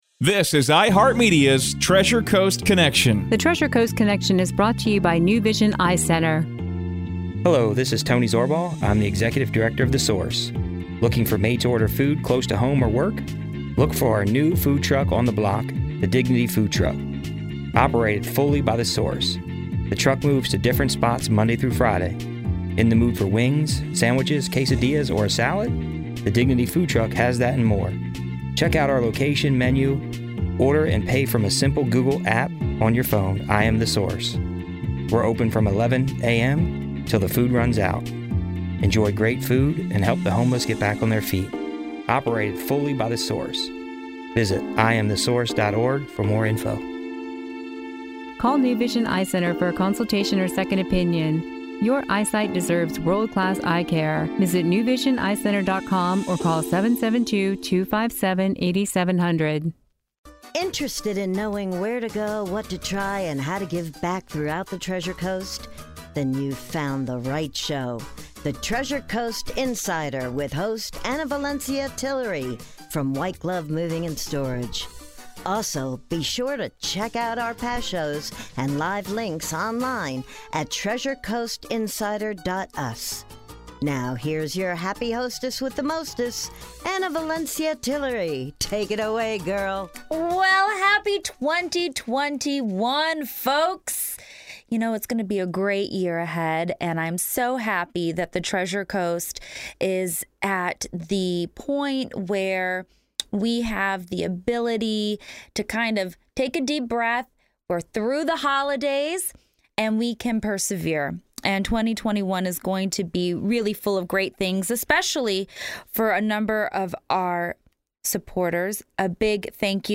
A terrific interview